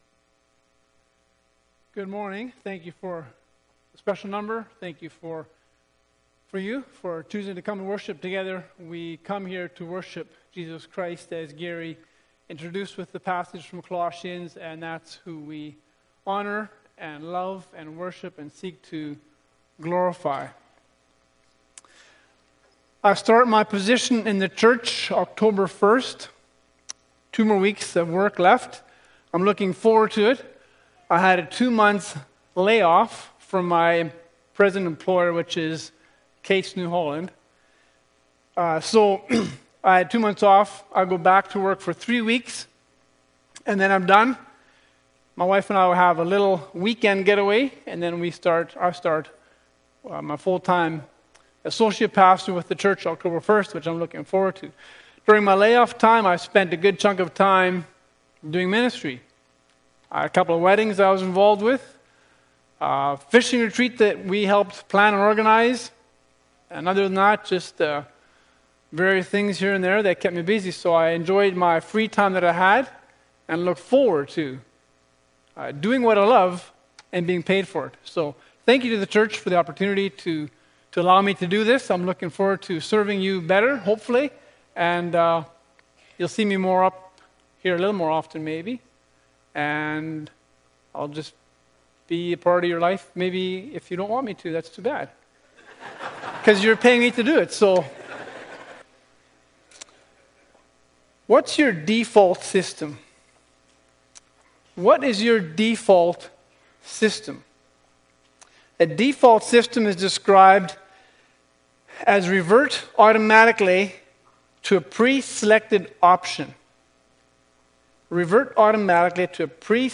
Bible Text: Isaiah 30 | Preacher